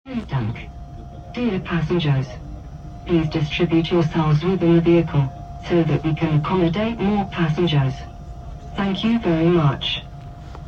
Absolutes Highlight ist die Durchsage für internationale Fahrgäste der Straßenbahnlinie 66 in Bonn.